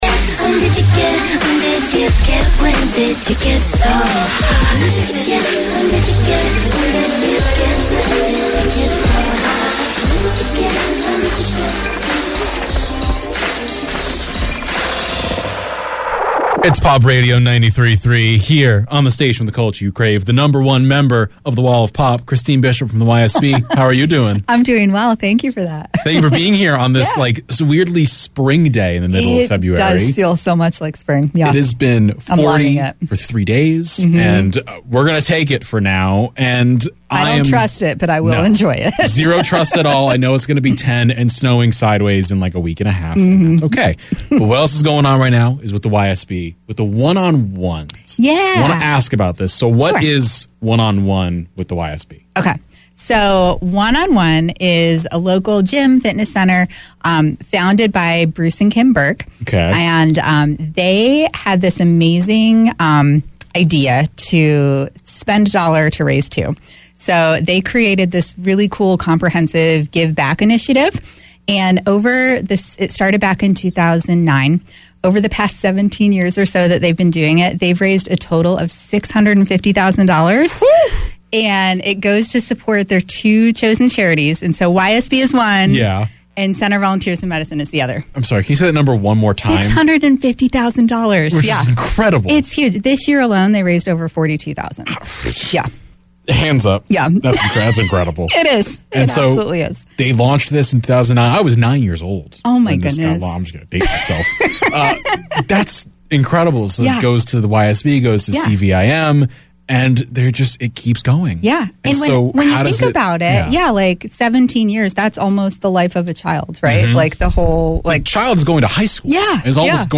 had to say about the impact of “Give Back” during her interview at It’s Pop Radio on February 17th